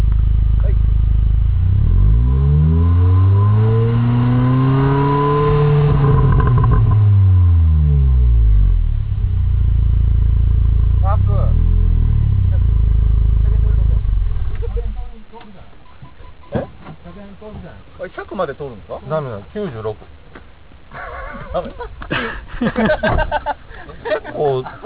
排気音測定オフ？